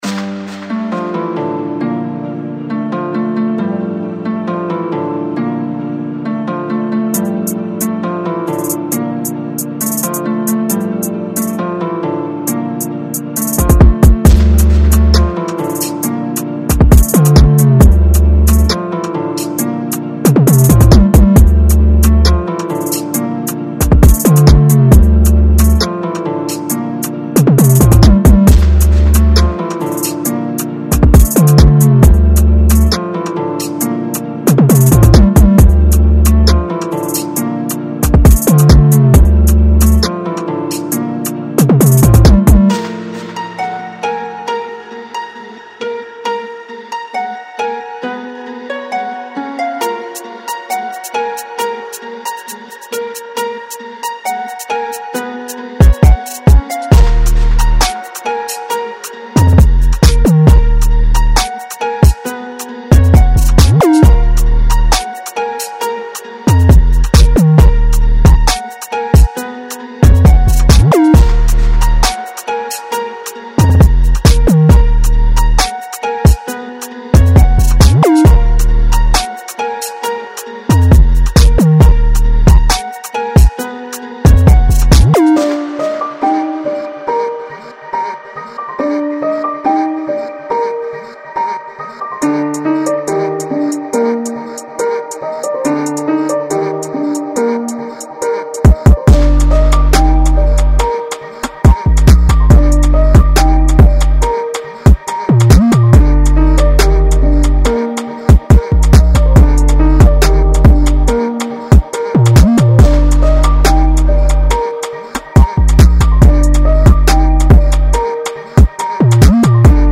Each loop is designed to deliver deep, resonant bass that’s perfect for the intense vibe of drill and trap music.
From rolling hi-hats to crisp snares, these loops provide the essential backbone for any drill or trap track.
Ranging from eerie and ethereal to sharp and gritty, these synths will add depth and texture to your productions.
3 Gritty Vocal Loops: Add an extra layer of intensity with these raw and powerful vocal loops.